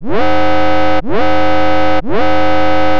se_alert.wav